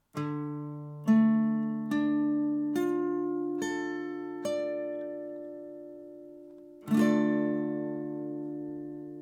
d-Moll (Barré, E-Saite)
D-Moll, Barre (E)
D-Moll-Barre-E.mp3